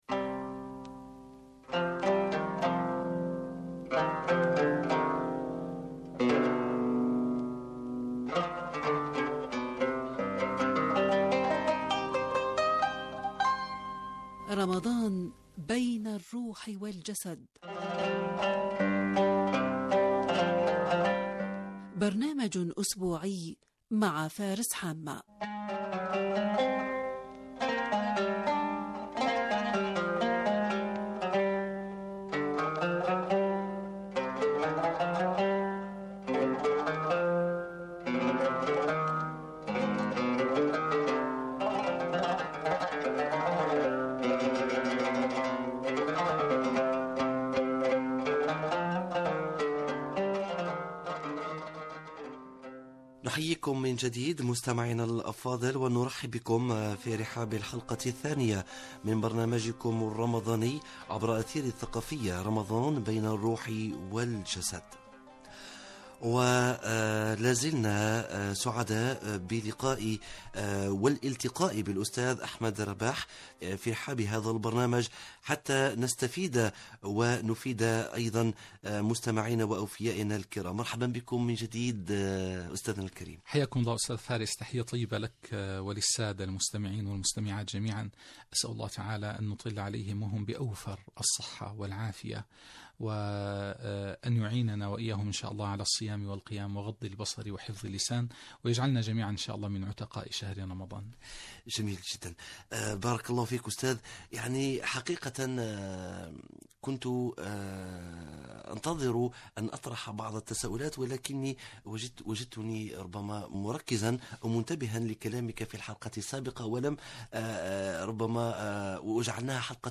لقاء على أثير الإذاعة الجزائرية - الإذاعة الثقافية